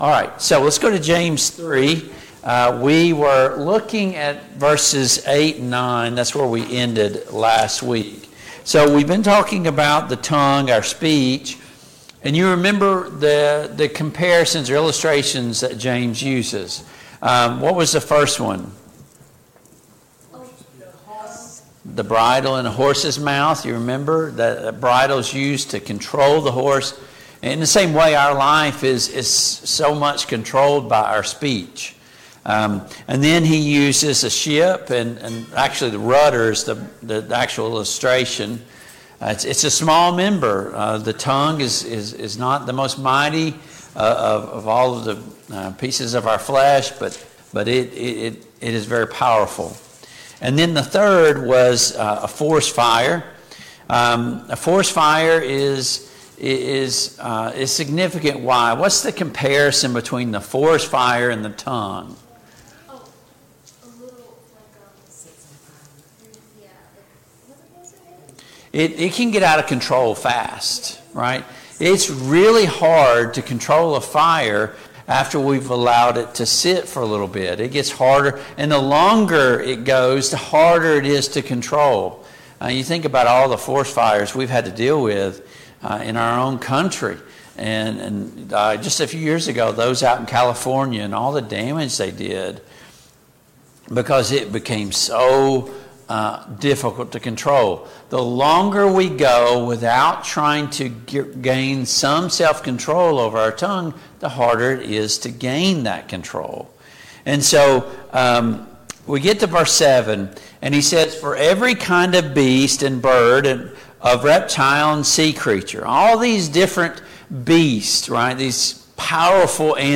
Service Type: Family Bible Hour Topics: Our Speech , Wisdom